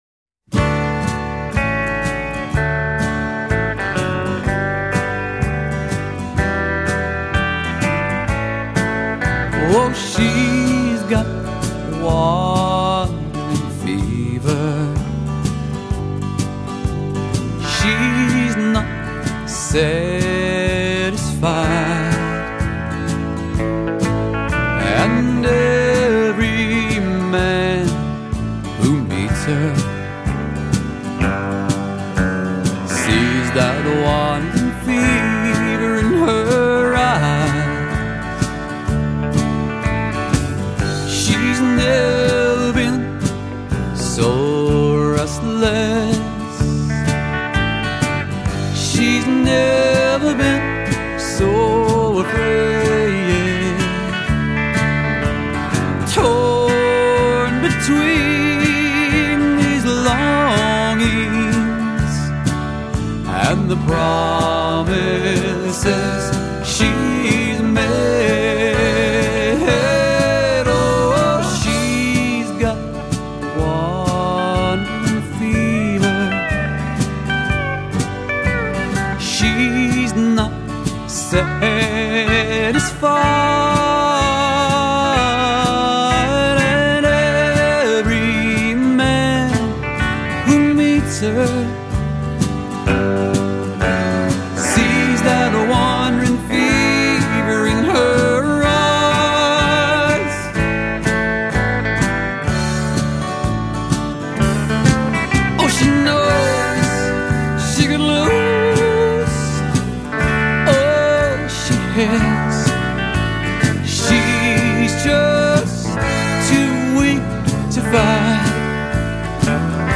vocals, guitar
Bass
Drums
Telecaster